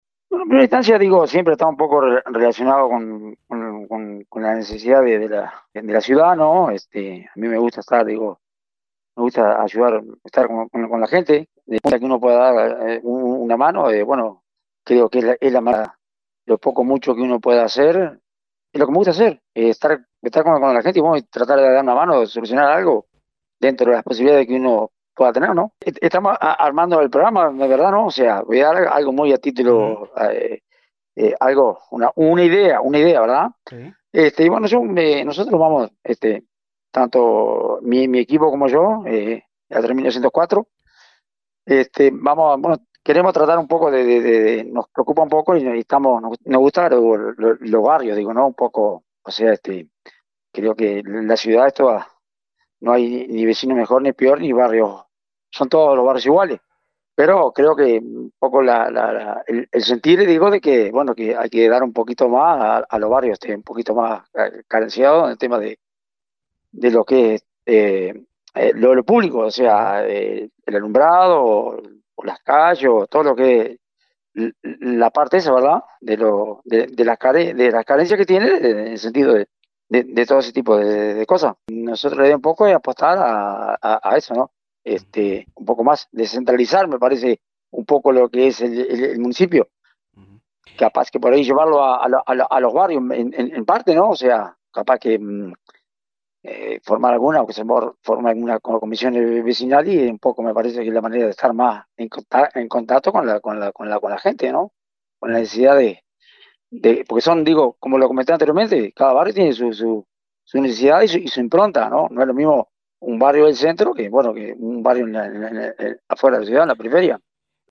En dialogo con Rocontenidos, Bermúdez destacó que su experiencia en el consejo municipal en anteriores administraciones le ha permitido  identificar y calibrar las necesidades de las zonas más carenciadas de servicios públicos e infraestructuras urbanas, necesidades a cubrir vinculadas todas a los cometidos propios del gobierno municipal.